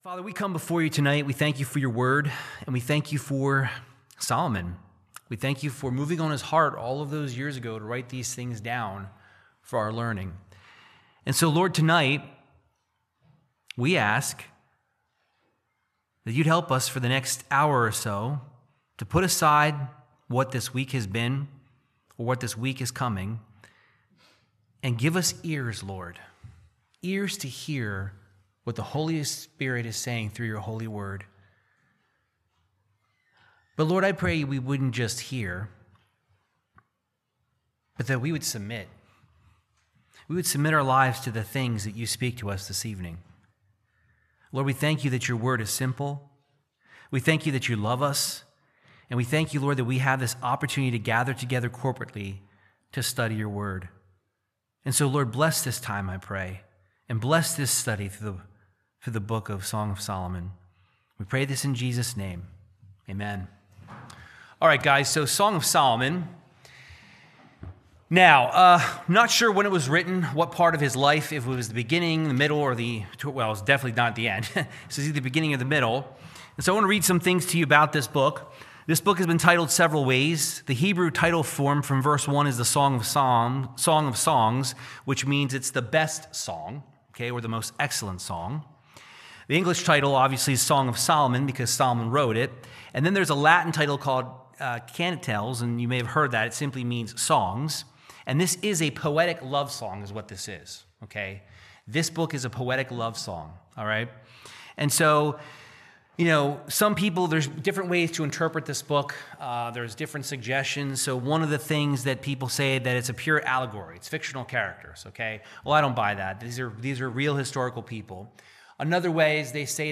Verse by Verse Bible Teaching of Song of Solomon 1-2